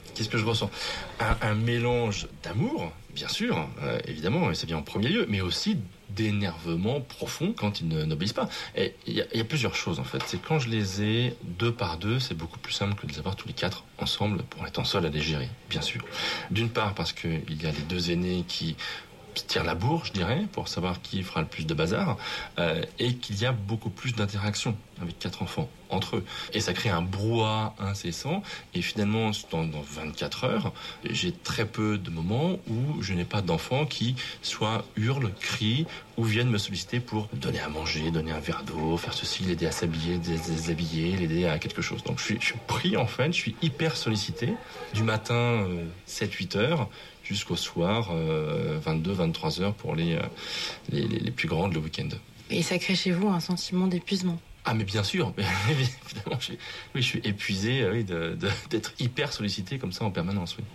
Allez, pour inverser un peu les rôles, je vous laisse aujourd’hui en compagnie d’un homme qui fait ce qui est encore souvent traditionnellement réservé aux femmes !
La prononciation qui mange les syllabes:
Je ne sais pas si vous entendez « que », mais pour une oreille française, ce petit mot, à peine prononcé, est cependant perceptible.
* que de les avoir: il dit « d’les », en raccourcissant « de ».
* je suis pris : devient « chuis »